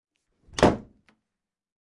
随机的" 门踢公共浴室的门撞开了砰的一声回响
描述：门踢公共卫生间门打开大满贯回声
标签： 公共 打开 SLAM 命中 浴室
声道立体声